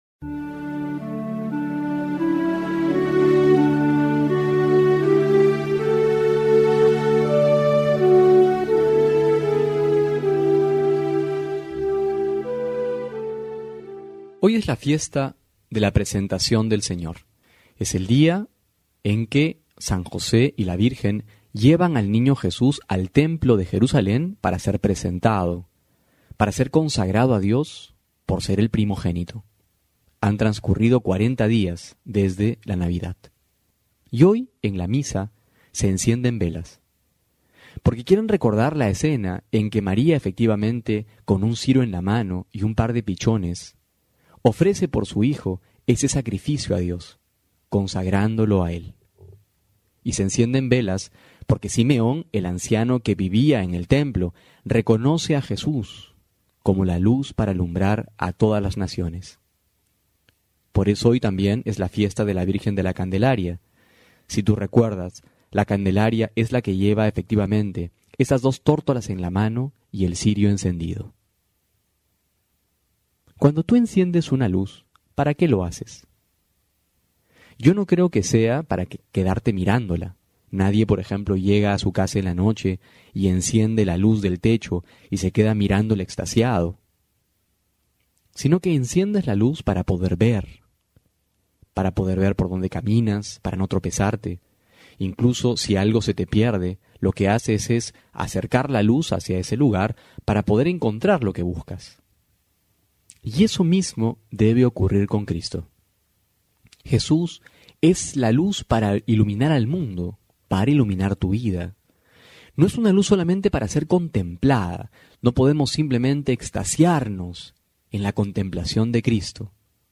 febrero02-12homilia.mp3